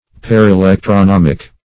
Search Result for " parelectronomic" : The Collaborative International Dictionary of English v.0.48: Parelectronomic \Par`e*lec`tro*nom"ic\, a. (Physiol.)